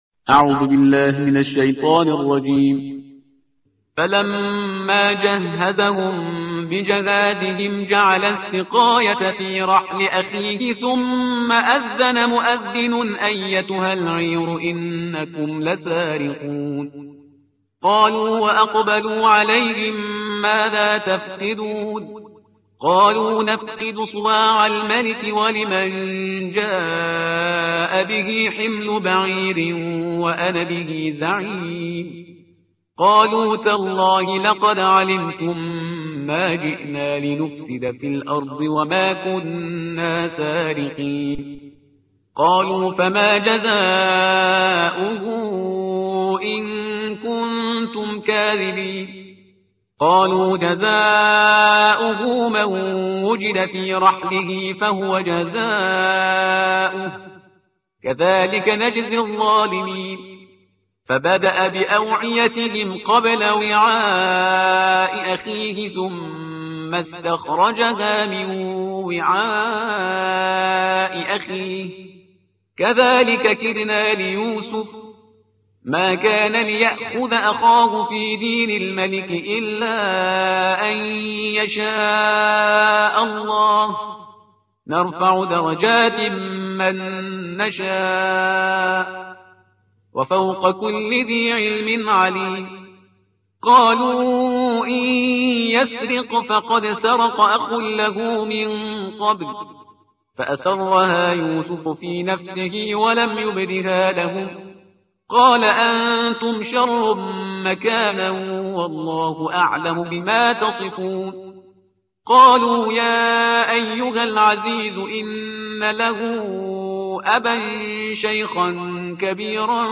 ترتیل صفحه 244 قرآن با صدای شهریار پرهیزگار